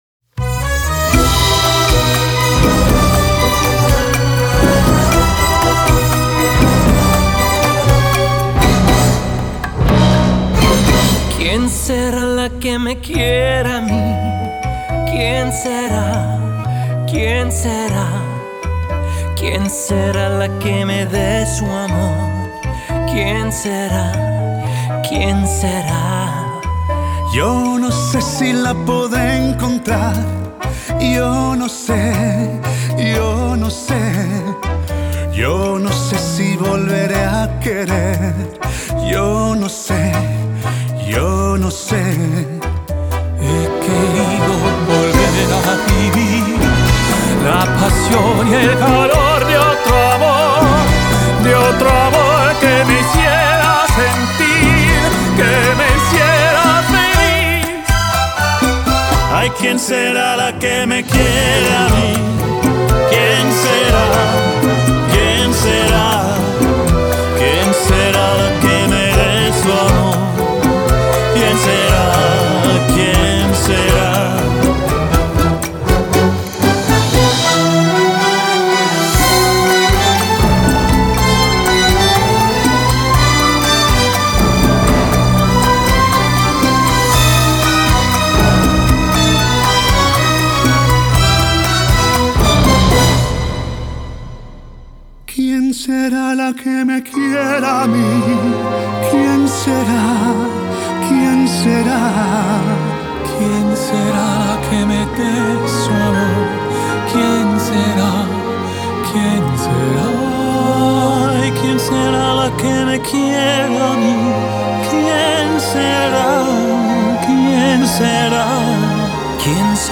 Genre: Classical Crossover